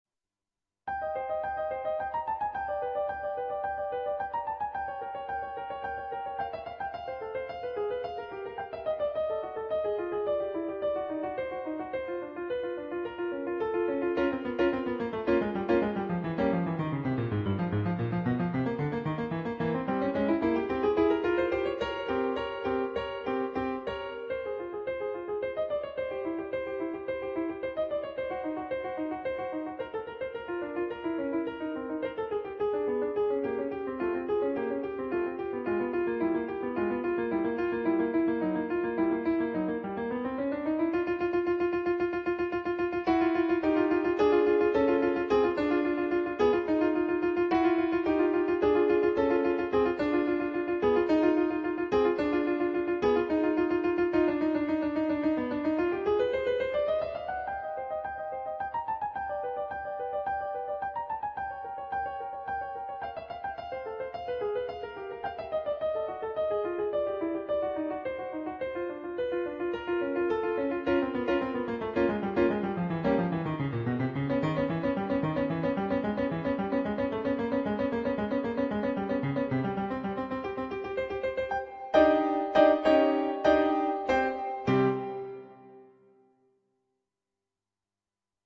on Yamaha digital pianos.